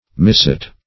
Misset \Mis*set"\ (m[i^]s*s[e^]t")